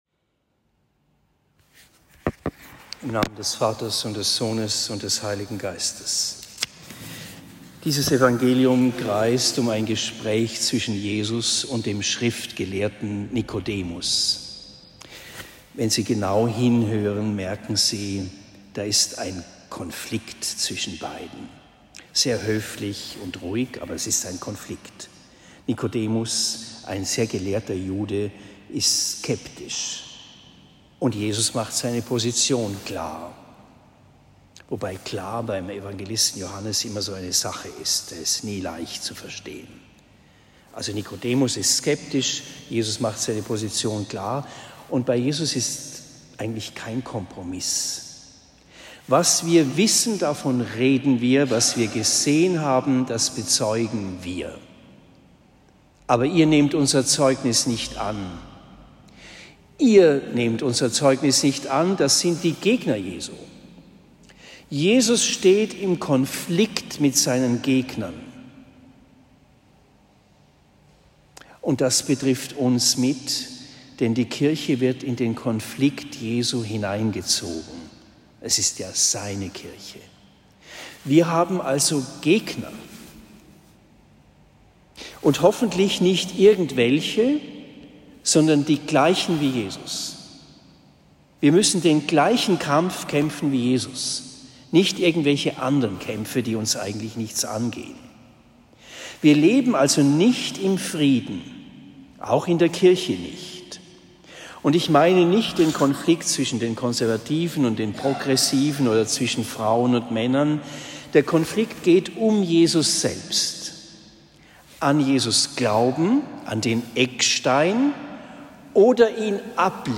Predigt in Oberndorf am 21. April 2023